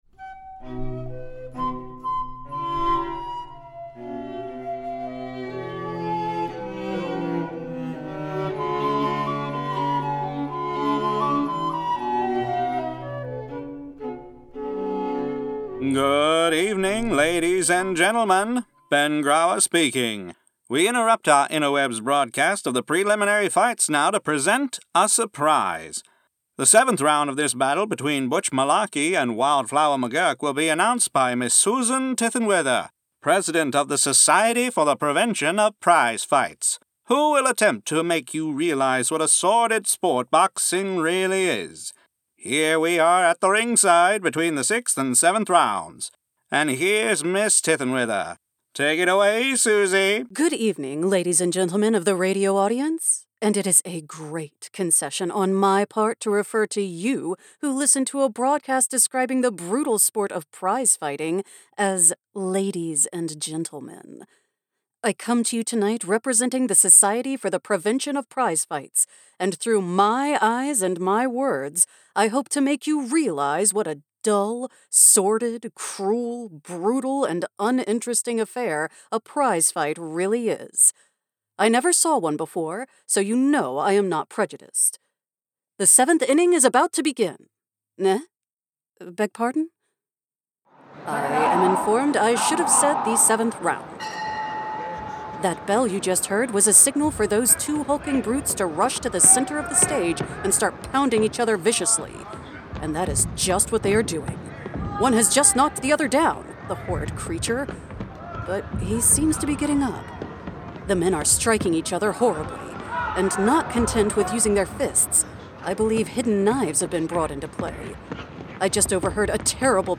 For those that don’t know; during the summer, the IDS recreates old radio programs to the best of our abilities for your entertainment and our amusement.
Quartet in G (Mozart) by American Baroque from Magnatune.